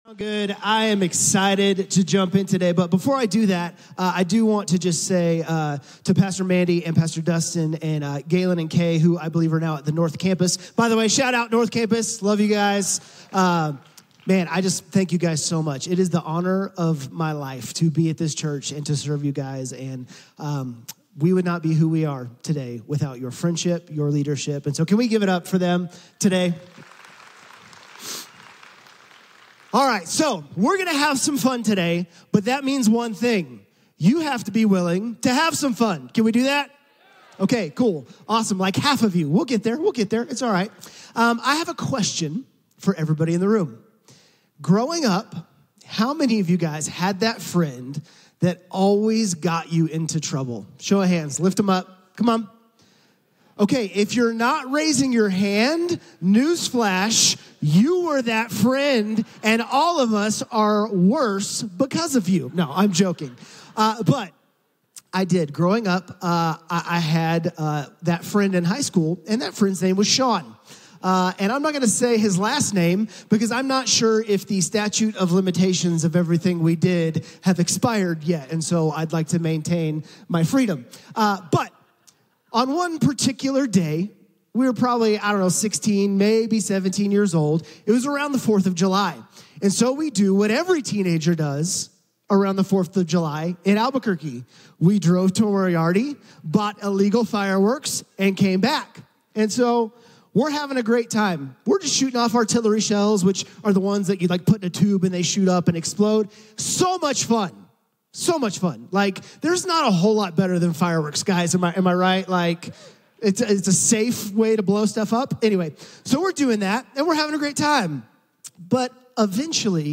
A message from the series "Summer in the Psalms." It’s easy to praise God during the good seasons, but it’s harder to praise Him during the bad.